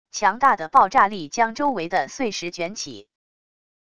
强大的爆炸力将周围的碎石卷起wav音频